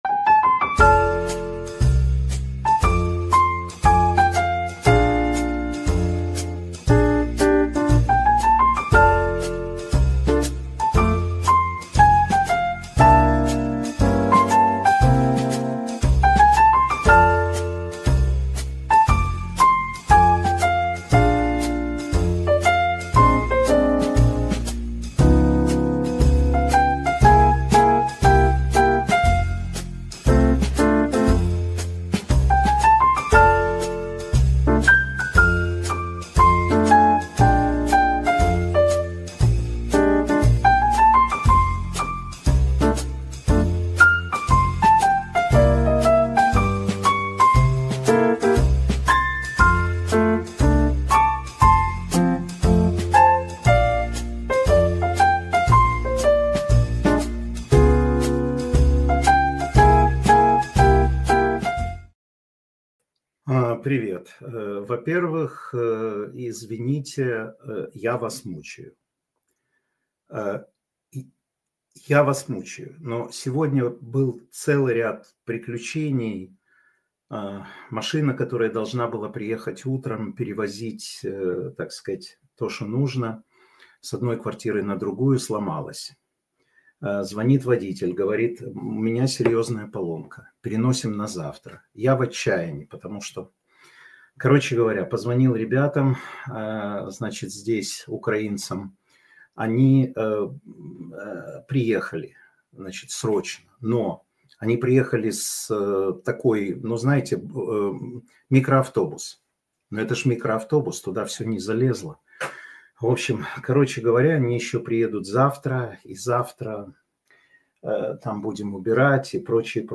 Информационно-аналитическая программа Матвея Ганапольского